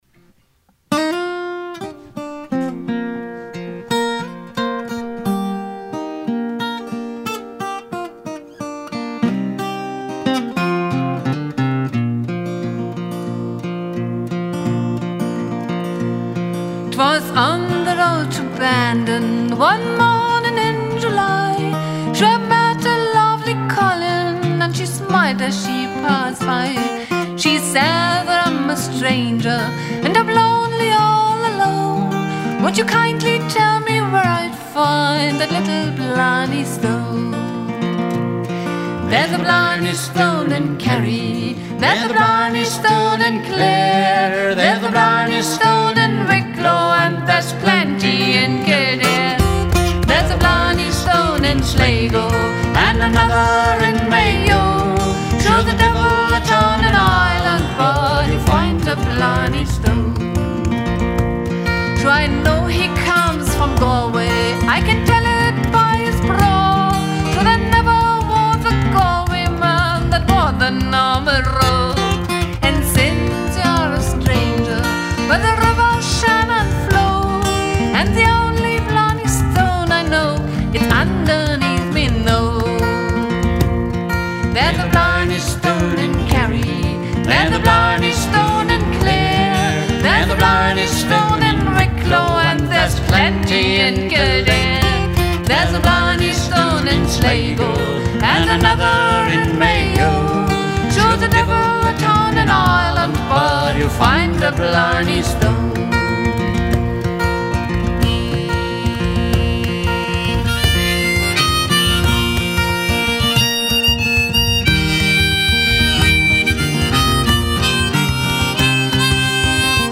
"Triangle" in Rehfelde